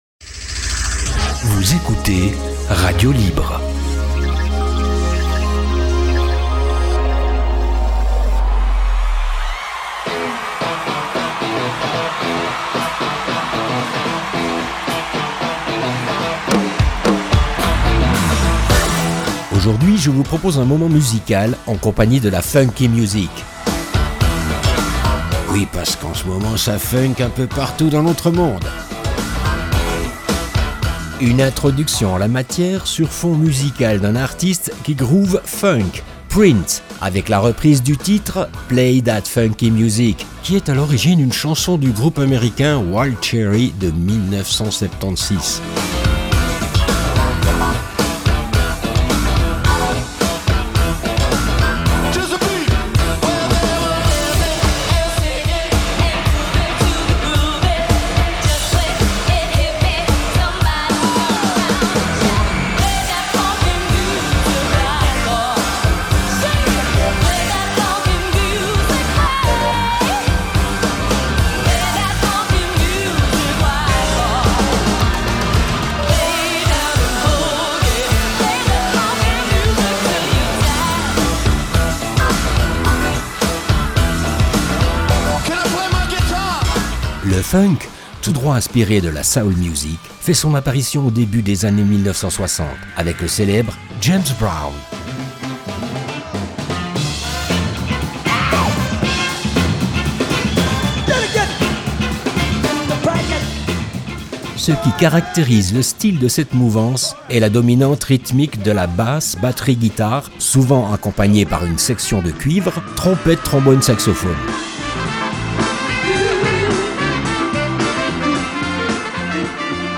Musique